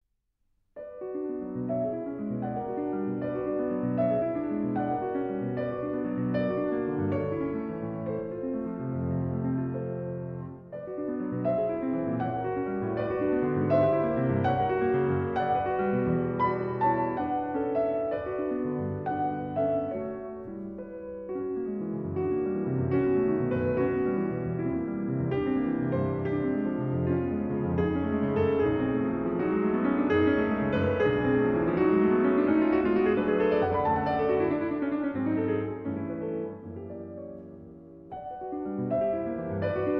Fantasia In G Minor, Op. 132